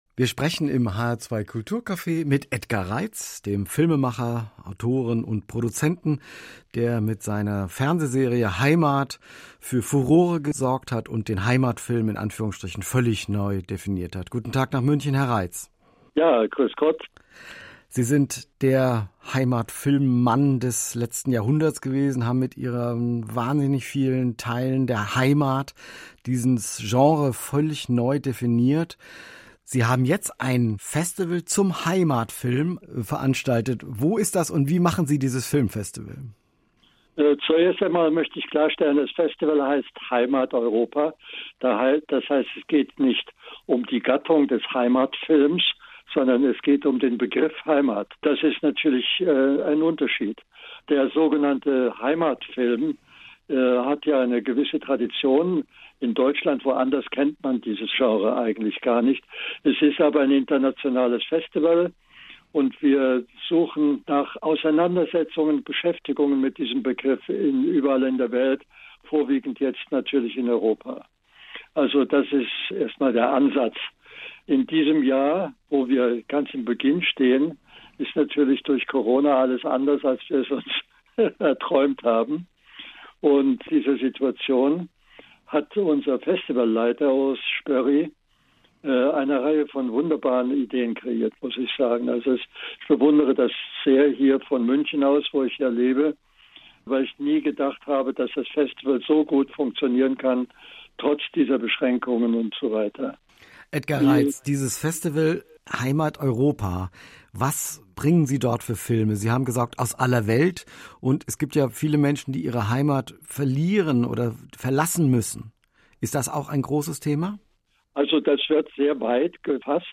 08.2020 - hr2 Kulturcafé - Gespräch mit Edgar Reitz 10.08.2020 - RPR 1 - Veranstaltungstipp 800 MAL EINSAM 14.08.2020 - RPR 1 - Veranstaltungstipp BUENA VISTA SOCIAL CLUB 15.08.2020 - RPR 1 - Veranstaltungstipp MAMMA MIA